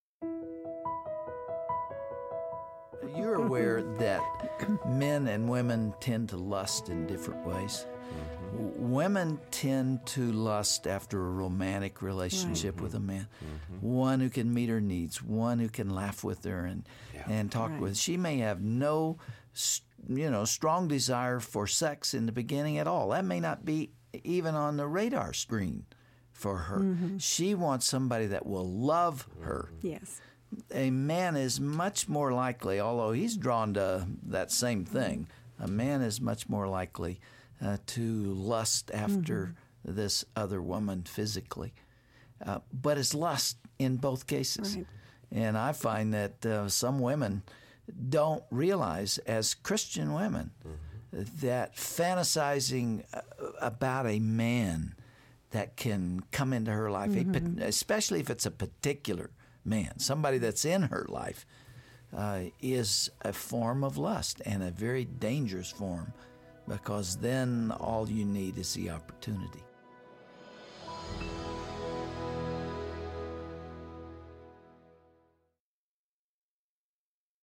Dr. Dobson talks about how men and women have different motivations for considering a temptation outside of marriage.